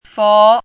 “佛”读音
国际音标：fo˧˥;/fu˧˥;/pi˥˧;/po˧˥
fó.mp3